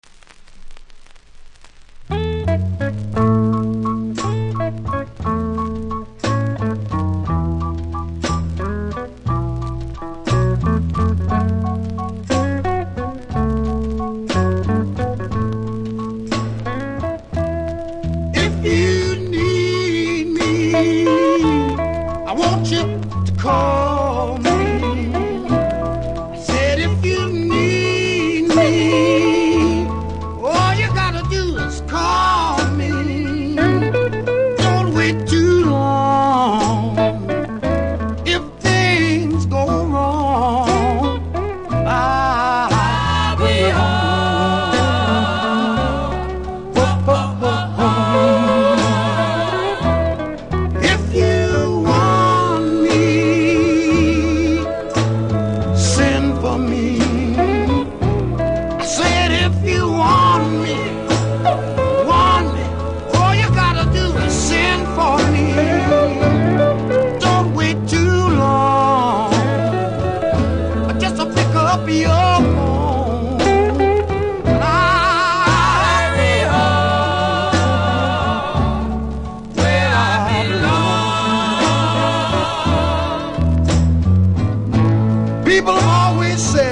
プレス起因のノイズ感じますので試聴で確認下さい。